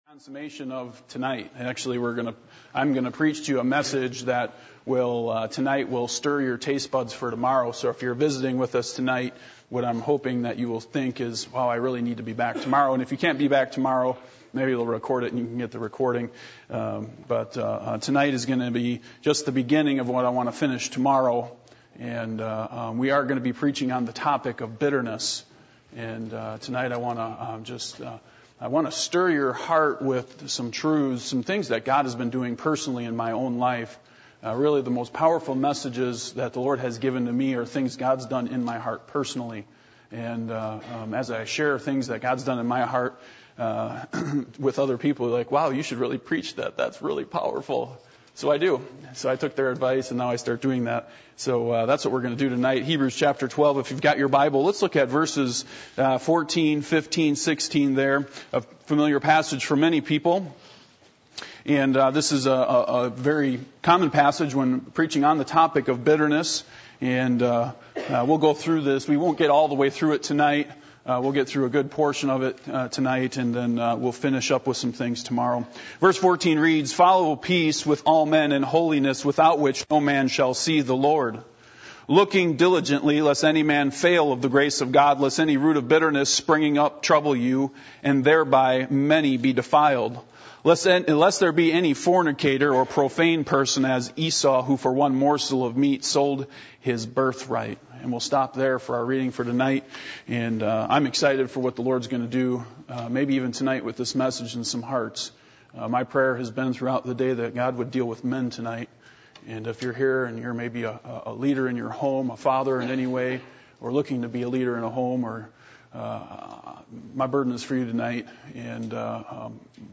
Hebrews 12:14-16 Service Type: Revival Meetings %todo_render% « The Crazy Man Are you Ready For This?